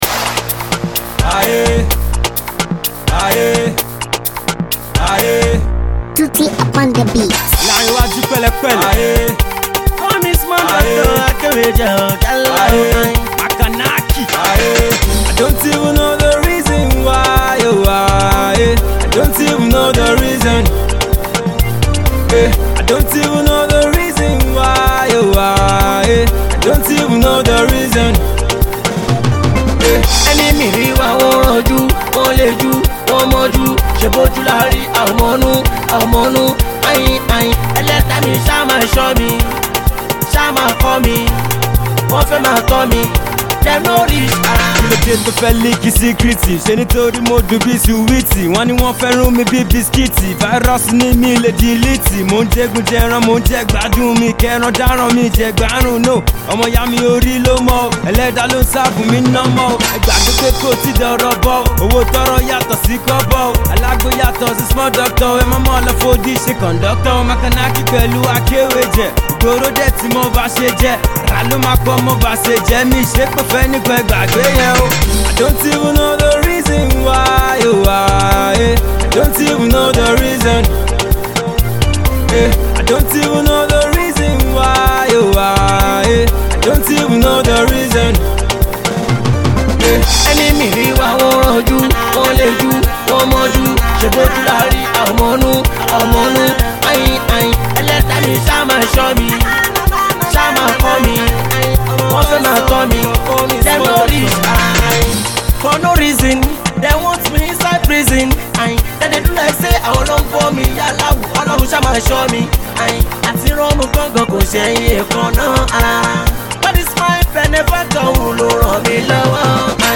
Alternative Rap